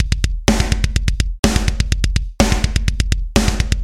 金属大鼓2 125bpm
描述：样式的金属桶...